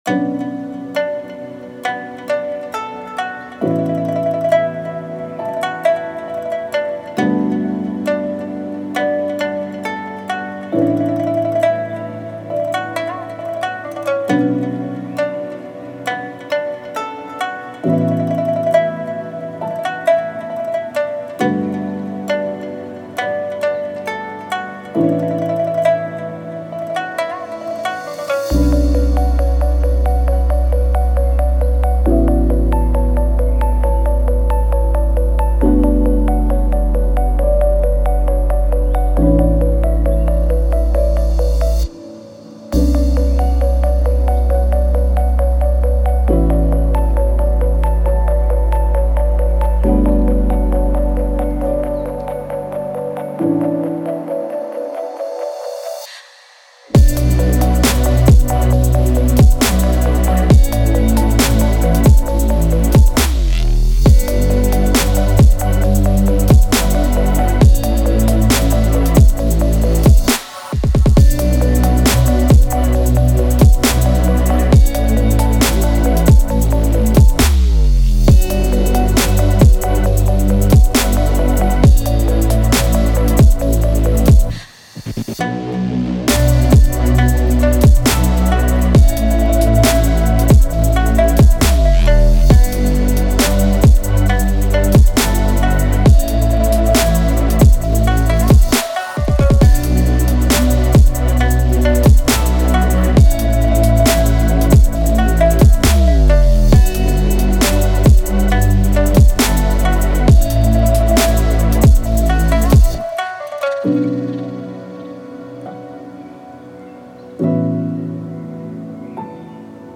chill-out и downtempo